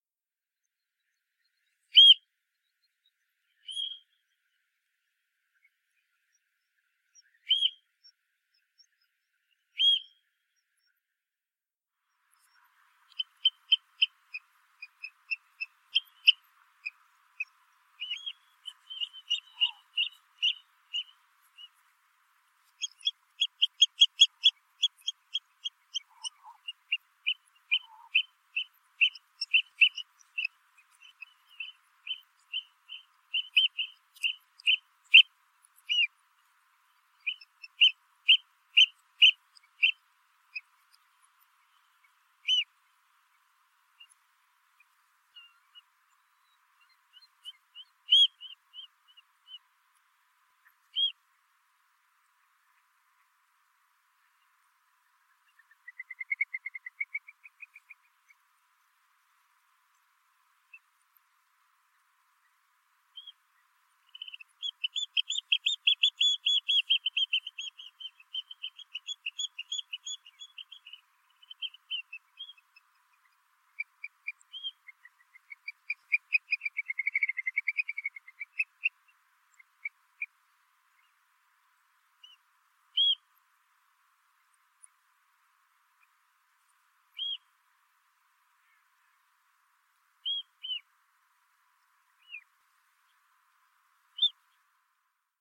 Beccaccia di mare
(Haematopus ostralegus)
Beccaccia-di-mare-Haematopus-ostralegus.mp3